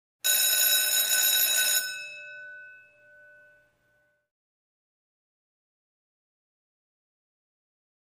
School Bell; Short Ring, Interior Hall, Close Perspective.